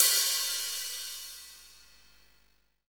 Index of /90_sSampleCDs/Roland - Rhythm Section/KIT_Drum Kits 3/KIT_Reggae Kit 1
HAT FUZN 0KL.wav